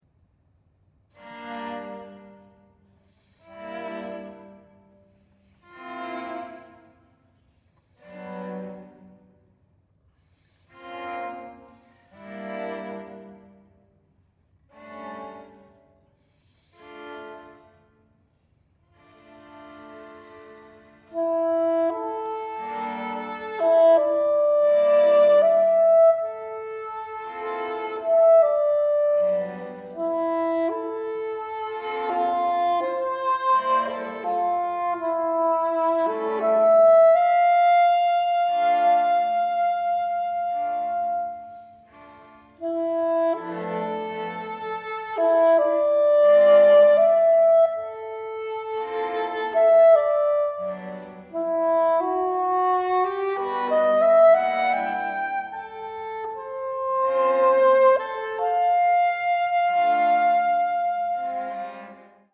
Longtime Duke University string quartet in residence
saxophone